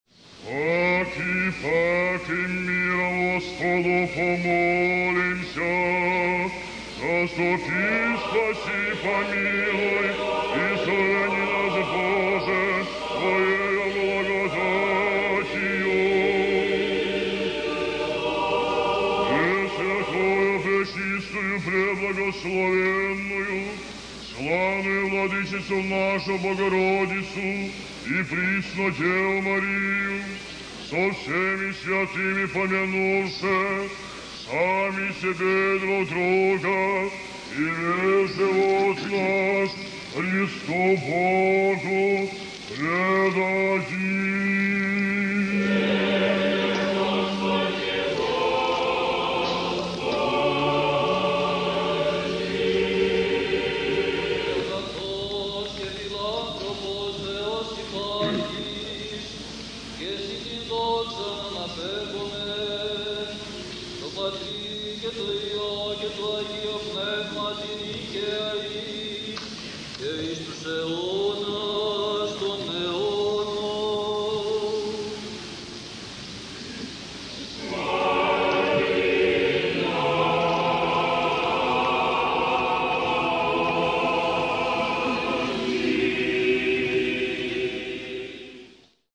Малая Ектиения